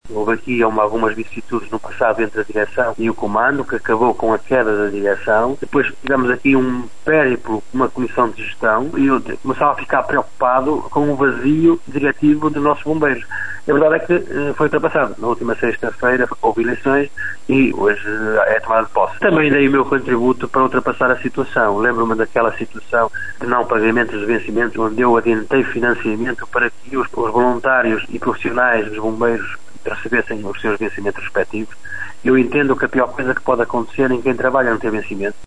Conta os pormenores deste processo o presidente da Câmara de Monção, Augusto Domingues.